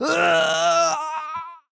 mobs_barbarian_death.ogg